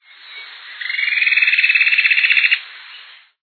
Vogelruf:
choquinha-estriada.mp3